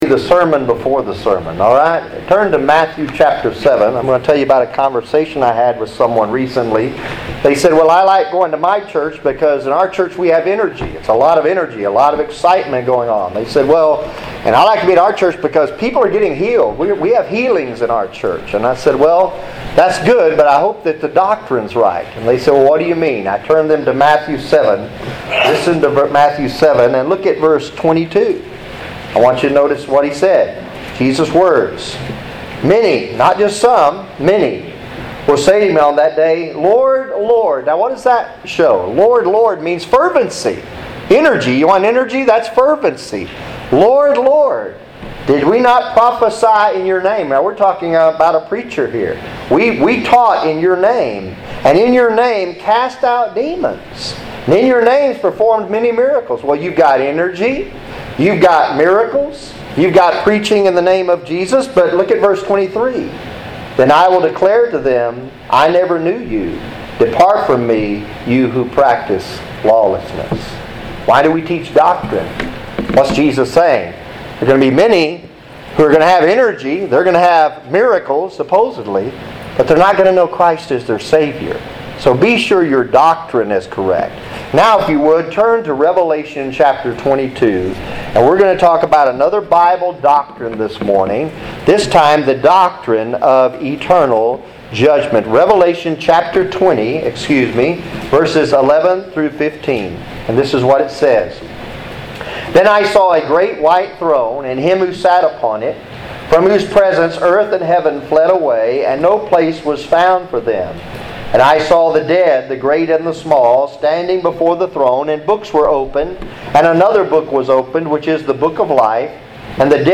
Sermons | Providential Baptist Church